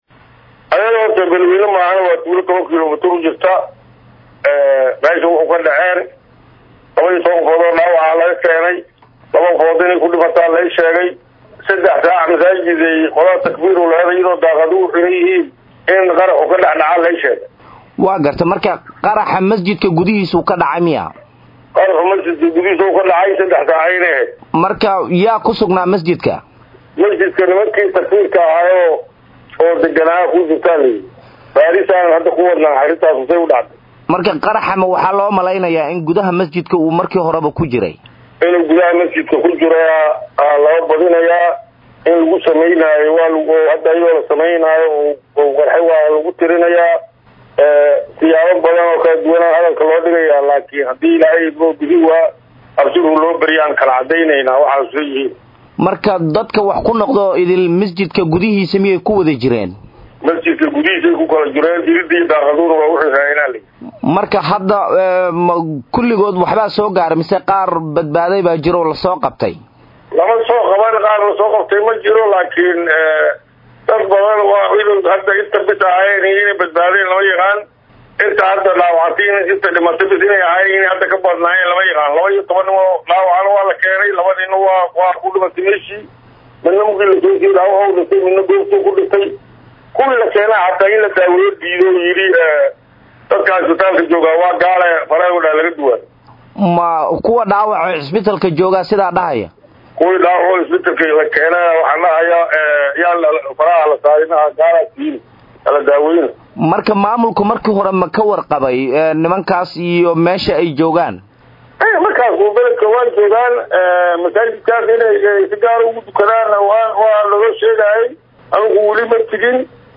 Wareysi-Guddoomiyaha-gobolka-Hiiraan-Dabageed-Okk.mp3